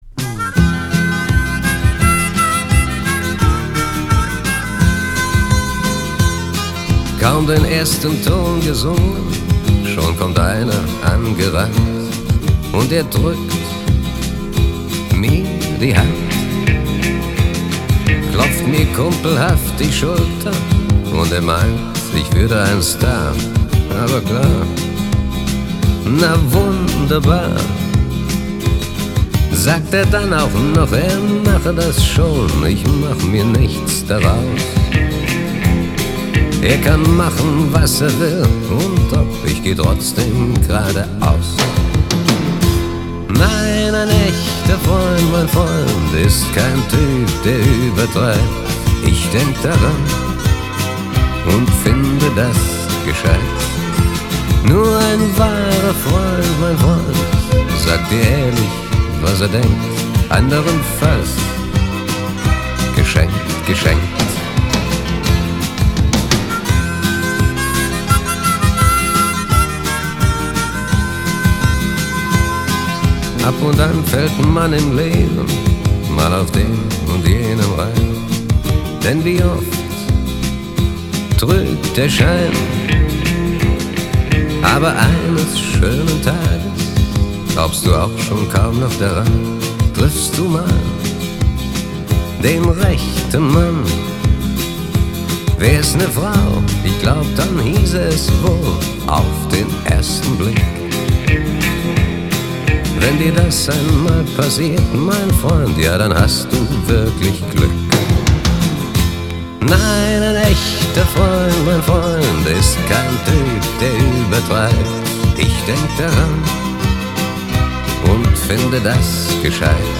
Vinil Rip